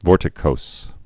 (vôrtĭ-kōs)